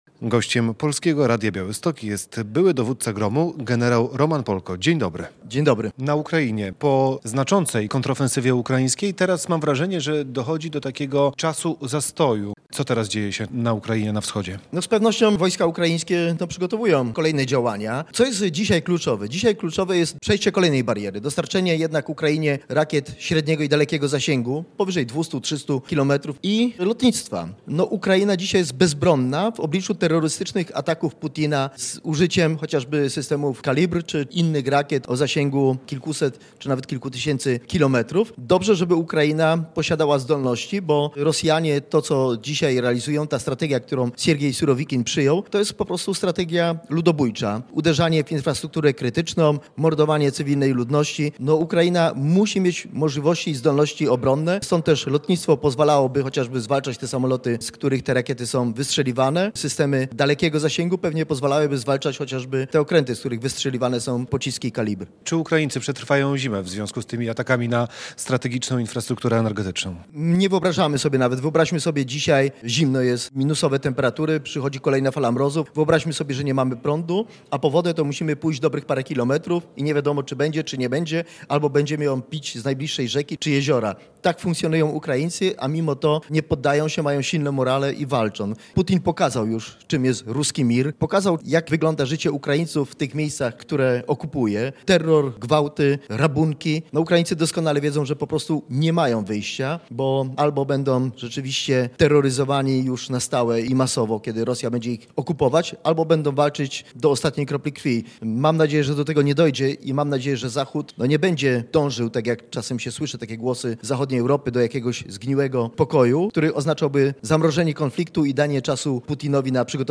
Radio Białystok | Gość | gen. Roman Polko - były dowódca jednostki wojskowej GROM